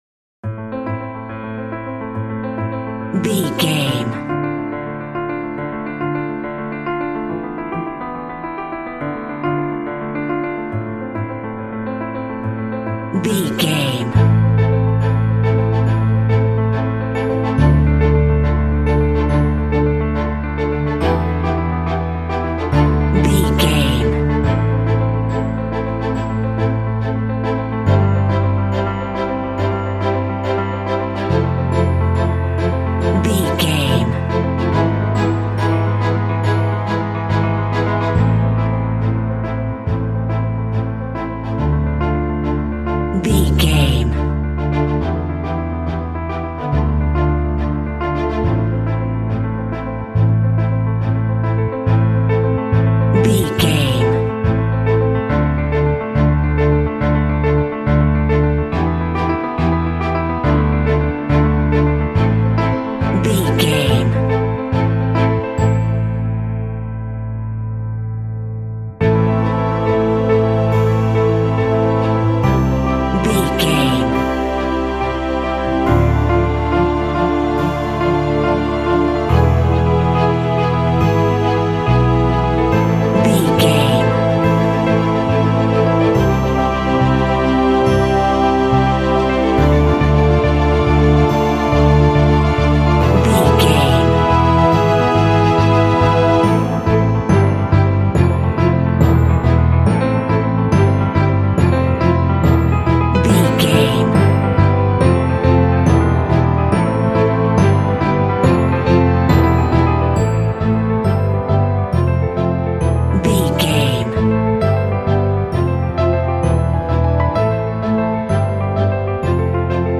Aeolian/Minor
Fast
sentimental
dreamy
strings
film score
classical guitar